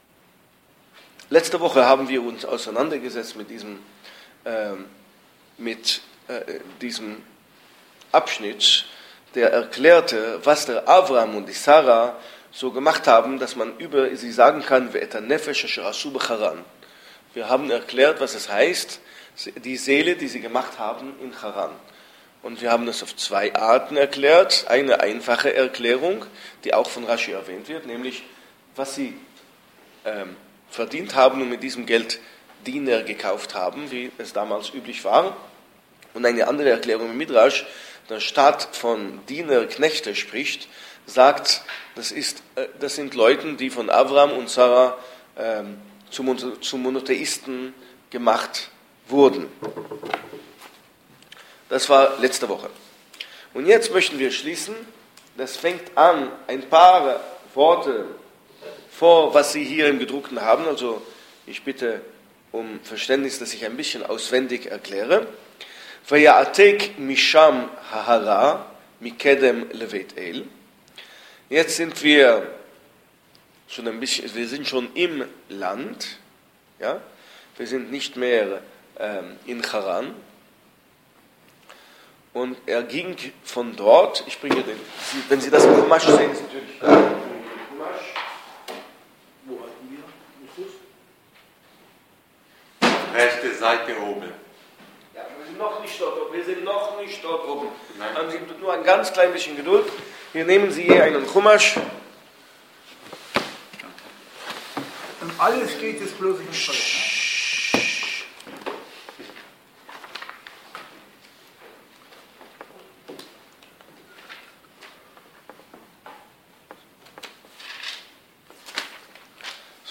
Zur Vortrags-Serie: Obwohl er eine der bedeutendsten biblischen Persönlichkeiten ist, erzählt die Torá immerhin nur einige ausgewählte Kapitel des Lebens Abrahams.